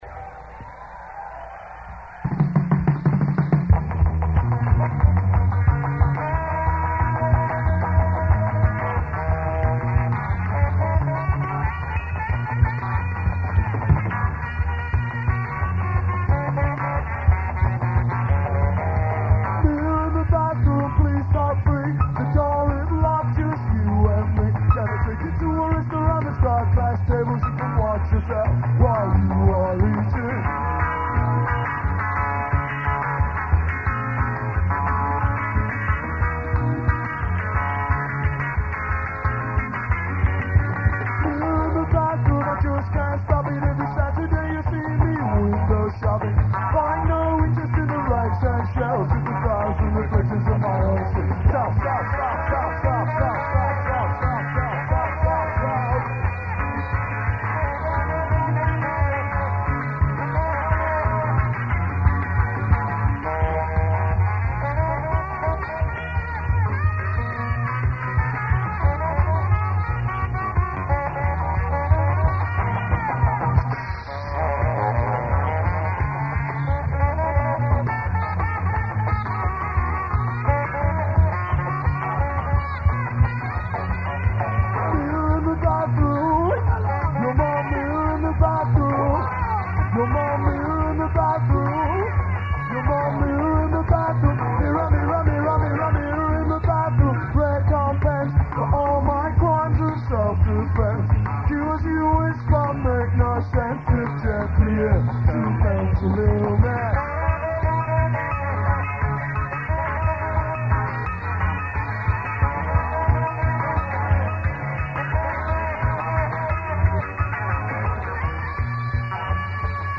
This song is a live recording of my favorite